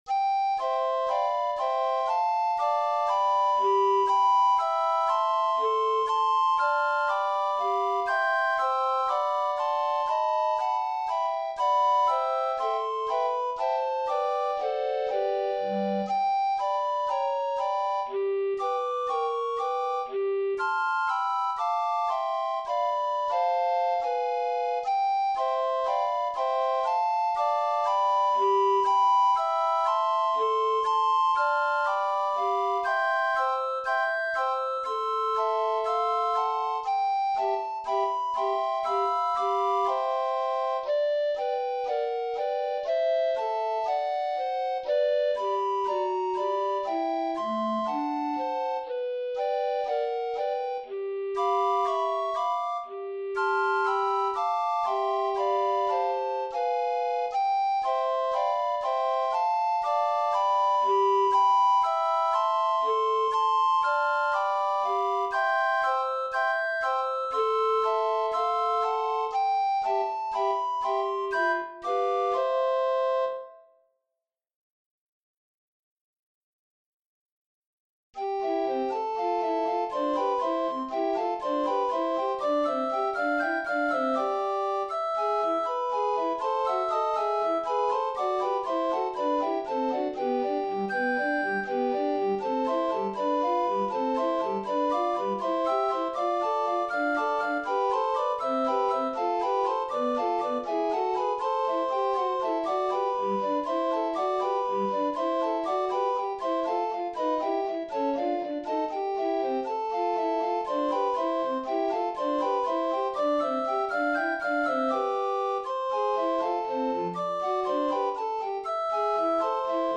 Piano version
Style: Classical
Transcribed for 4 Recorders